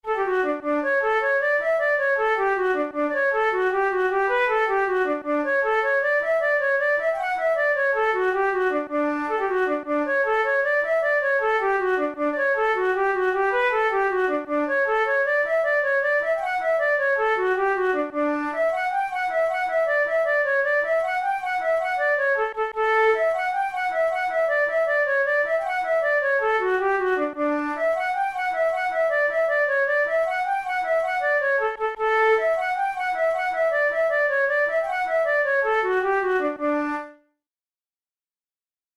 InstrumentationFlute solo
KeyD major
Time signature6/8
Tempo104 BPM
Jigs, Traditional/Folk
Traditional Irish jig